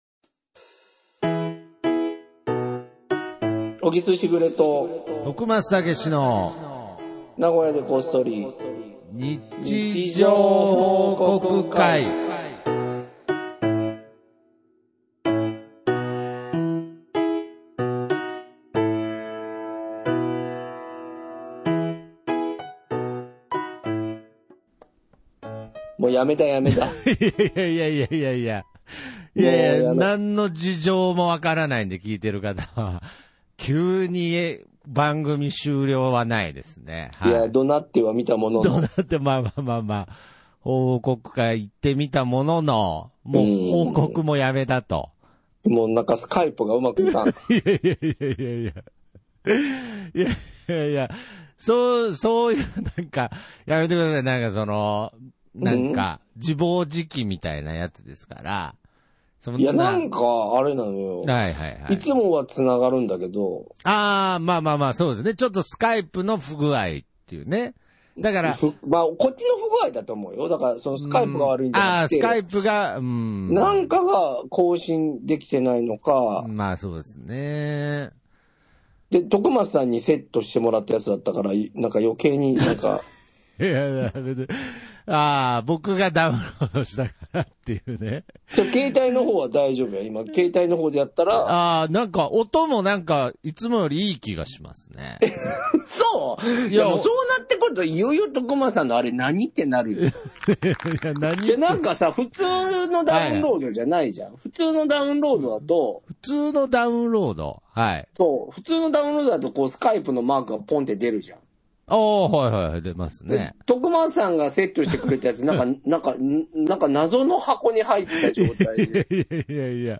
なんであのとき放送局は同級生の中年三人でやっている放送局ごっこ遊びです。